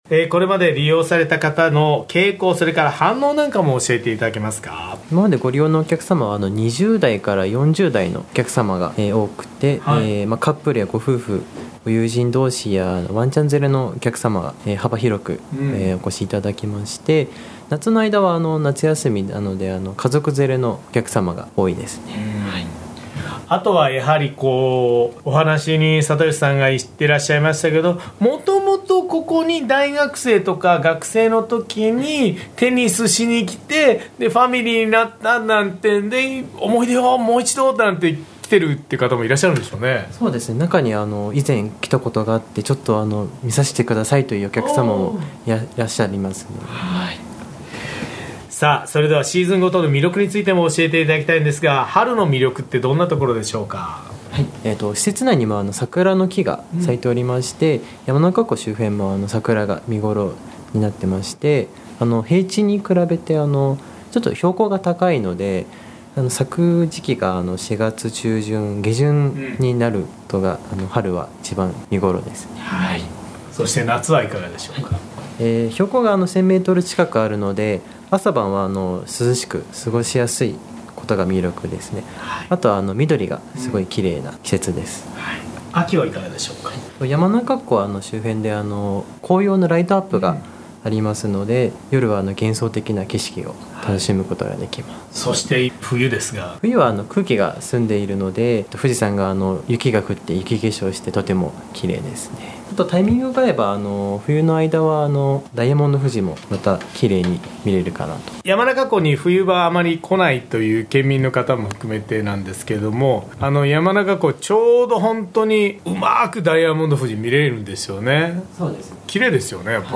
毎週土曜午前11時から生放送。